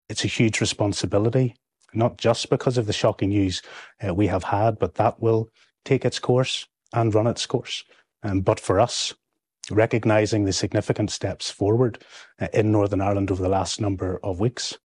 Gavin Robinson has been appointed as interim leader.
He says they were made aware of the allegations late Thursday: